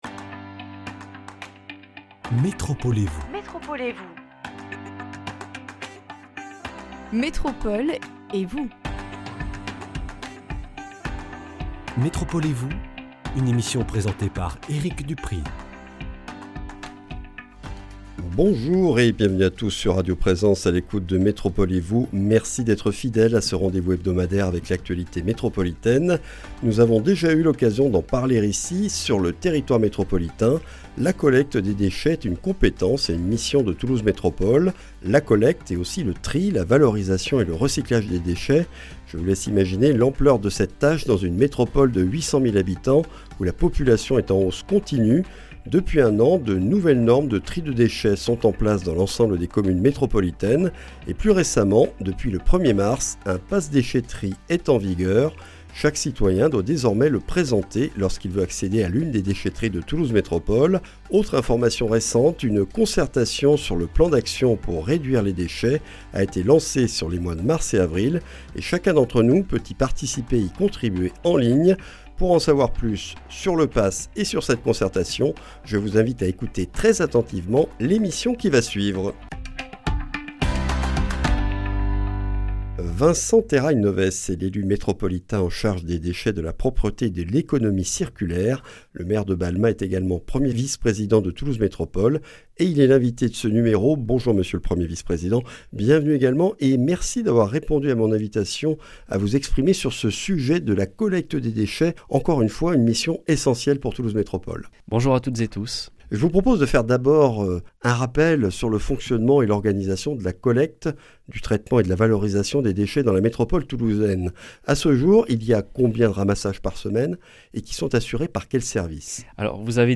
Vincent Terrail-Novès, maire de Balma, 1er vice-président Toulouse Métropole chargé de l’Économie circulaire, des Déchets et de la Propreté, est l’invité de "Métropole et vous !". Il revient sur le Pass Déchèterie, obligatoire depuis le 1er mars 2024, et nous présente les objectifs de la concertation en ligne en vue de l’élaboration du Plan de réduction des déchets pour 2025-2030.